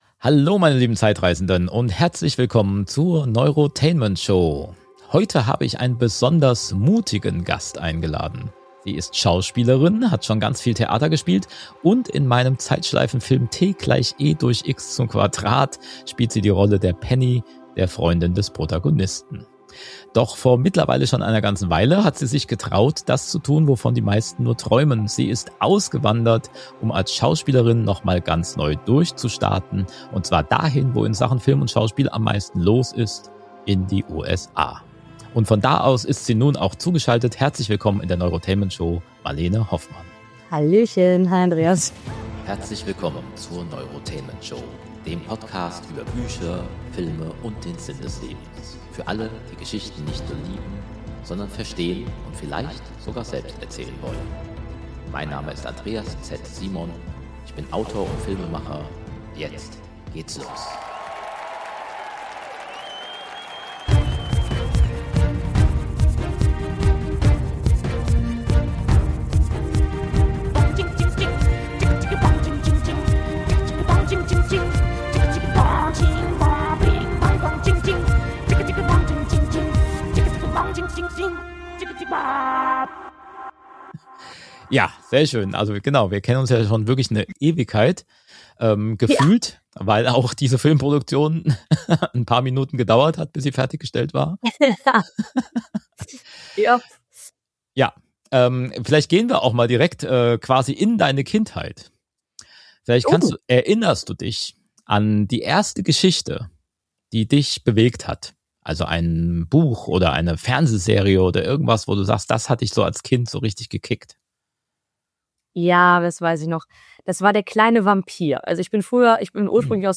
Ein inspirierendes Gespräch über Schauspiel, das Abenteuer des Neuanfangs und die Frage, was uns wirklich antreibt.